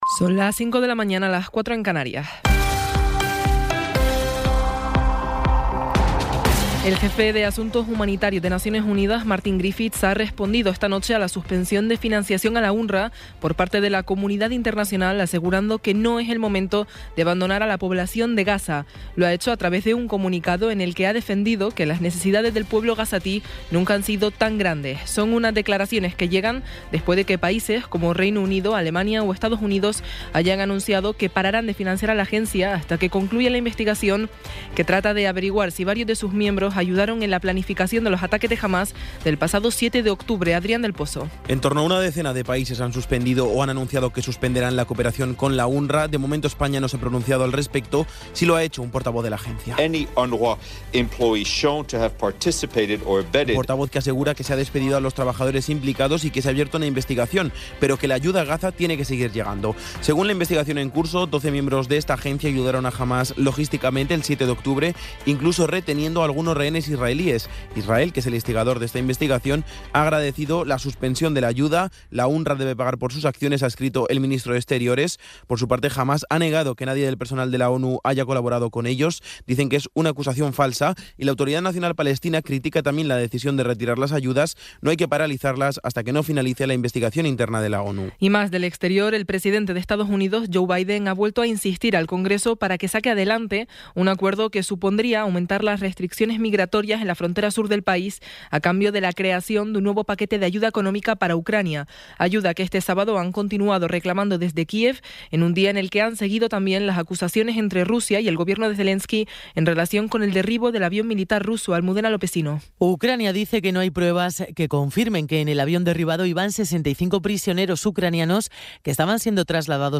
Resumen informativo con las noticias más destacadas del 28 de enero de 2024 a las cinco de la mañana.